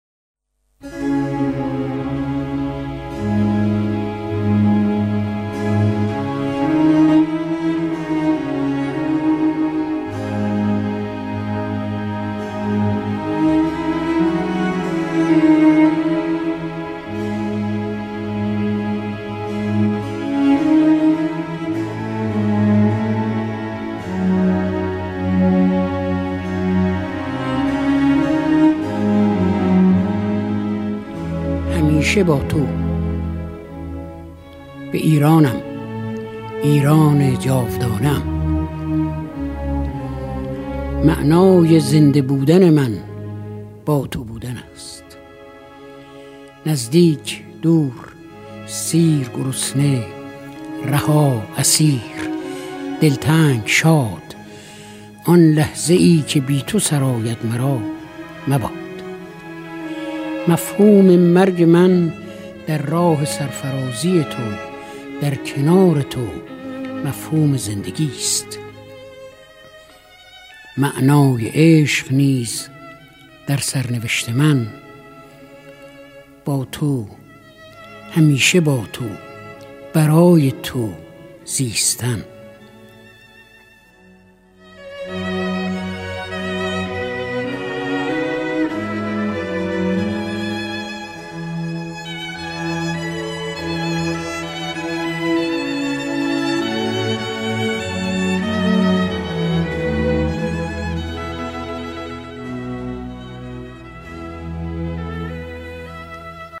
آلبوم شب شعر فریدون مشیری (شعر و صدای شاعر) | ضیاءالصالحین
از سری مجموعه های شعر و صدای شاعر؛ در آلبوم شب شعر فریدون مشیری با صدای خودش ۱۲ شعر سروده شده اش را می خواند که شعر محبوب “کوچه” نیز جز آنها می باشد.